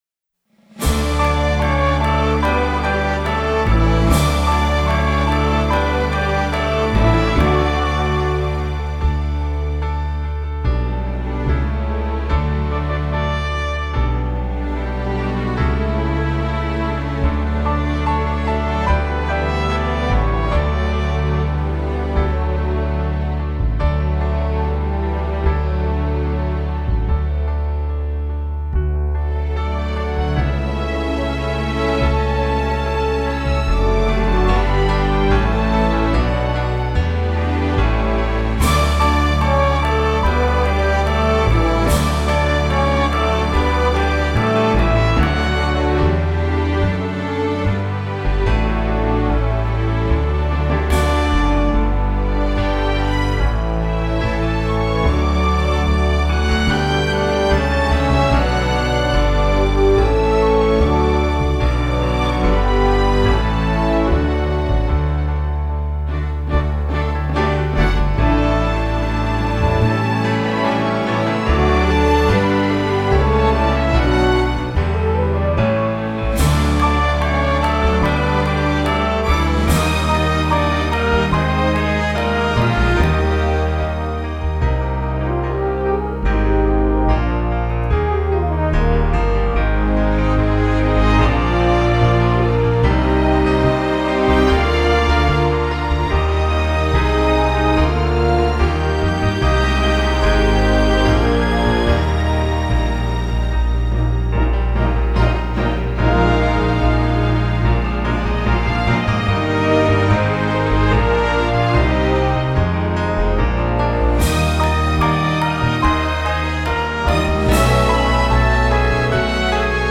Образец исполнения: